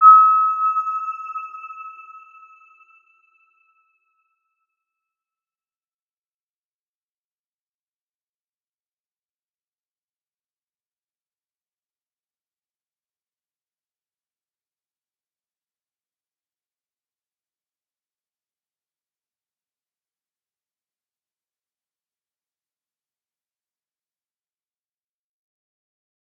Round-Bell-E6-mf.wav